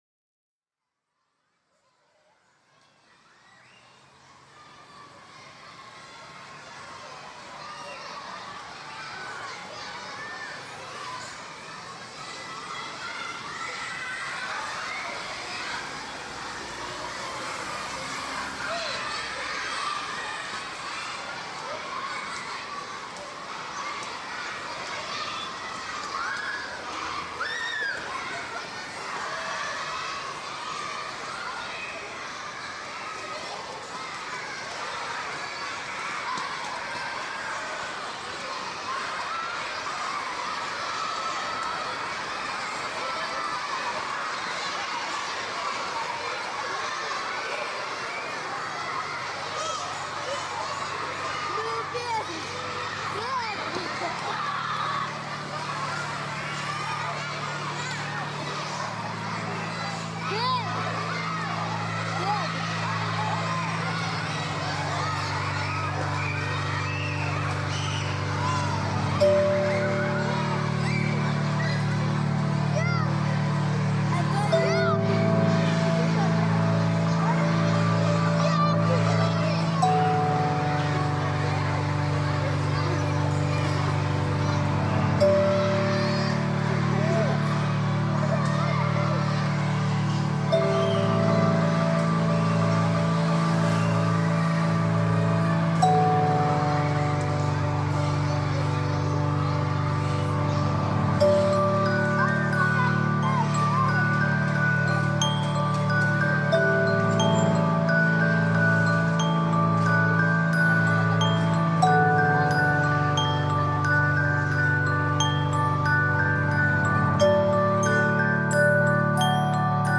ambient/experimental electronic